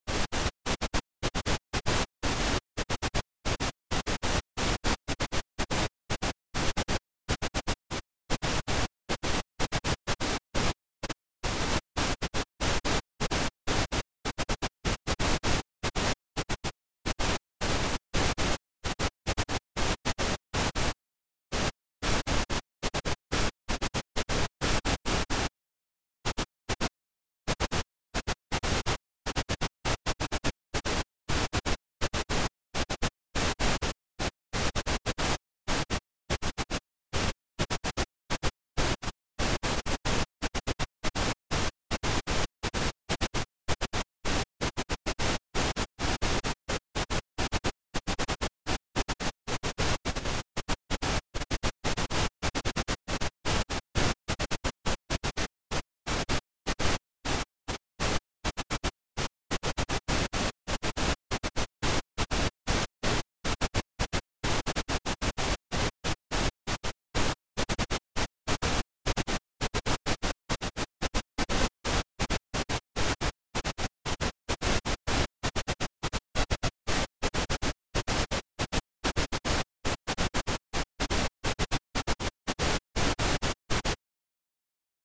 Since the goal here is to produce a signal that varies only in volume, I instead substitute a sample of pink noise.
6.3—I haven't had much luck finding good specimens of actual, original Morse code tapes, but an example was printed at second hand in the New York Herald of January 8, 1848. It's somewhat garbled, but I give it here as a proof of concept:
morse-1848.mp3